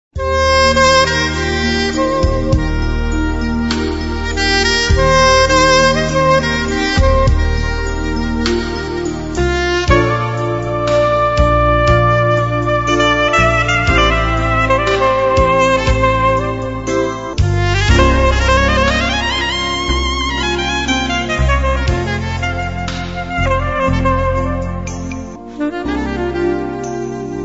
1 – نغمات موسيقى هادئة وحزينة